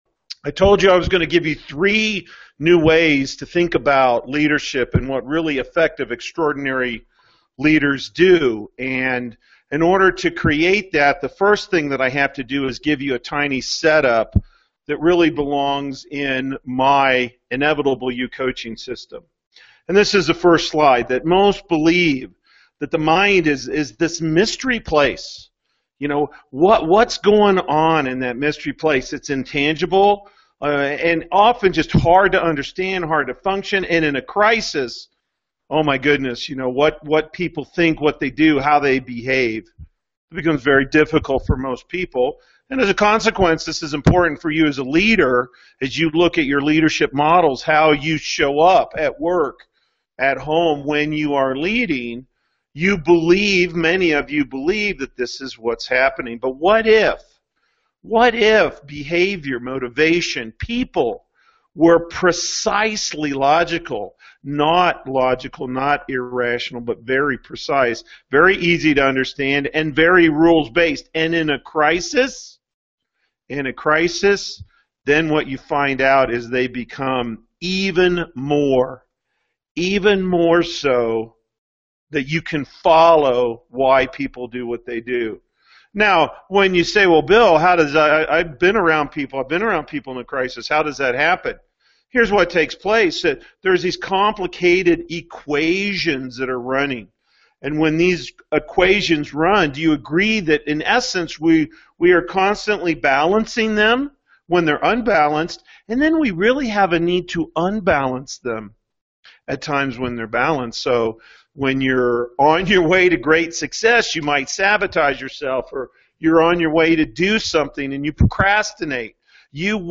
Leaders+vs+managers+webinar+3-20-14.mp3